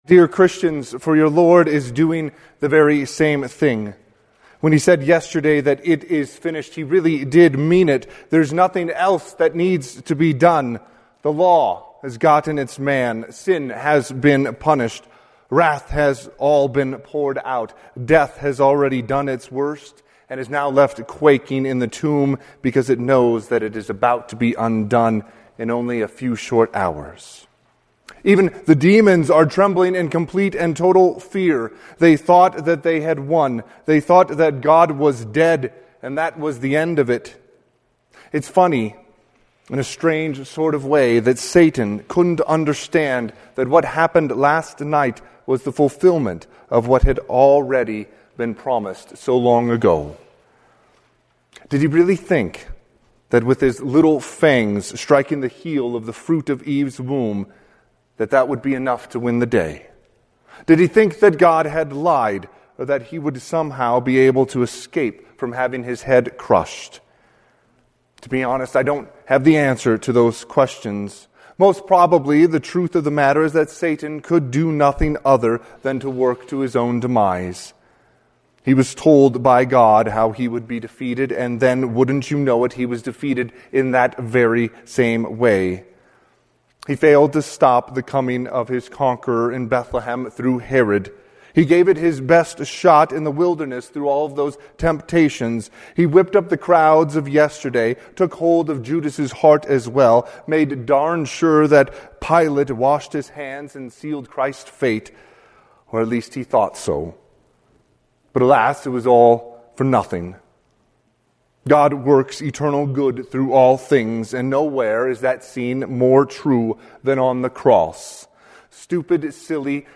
Sermon – 4/4/2026 - Wheat Ridge Evangelical Lutheran Church, Wheat Ridge, Colorado